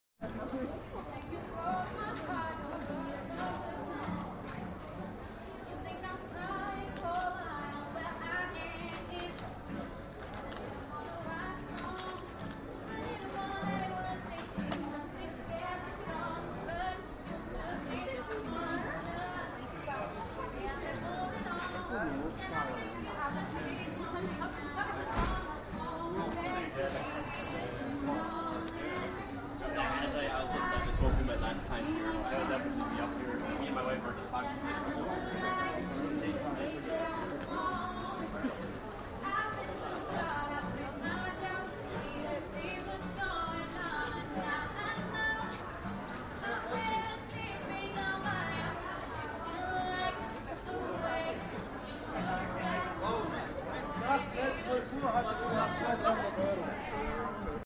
Street musicians Old Town Stockholm